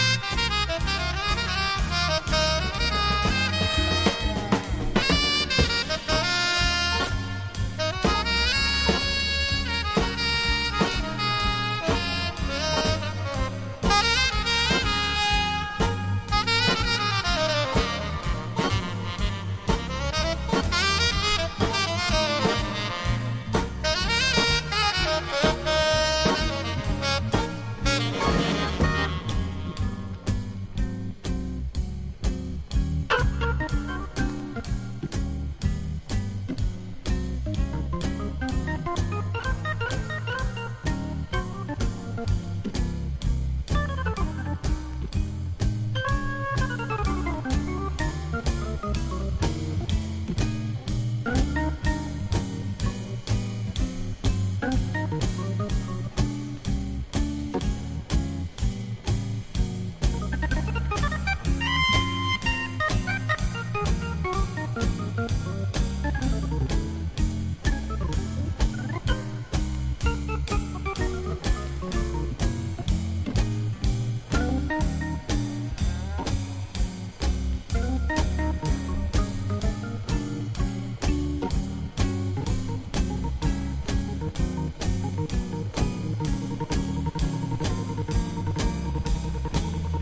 アメリカのブルース～ジャズ・オルガニスト
ミドルテンポのラウンジジャズB1や仄かにリバーブの効いたサックスが気持ちイイB3などおすすめです。